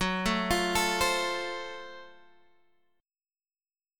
GbmM7b5 chord